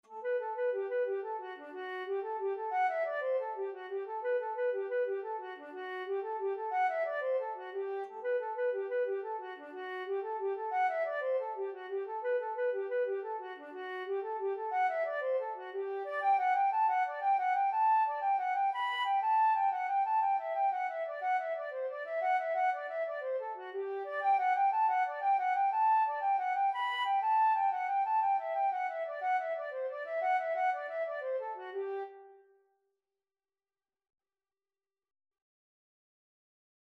Traditional Trad. Hinchy's Delight (Irish Folk Song) Flute version
G major (Sounding Pitch) (View more G major Music for Flute )
6/8 (View more 6/8 Music)
D5-B6
Flute  (View more Easy Flute Music)
Traditional (View more Traditional Flute Music)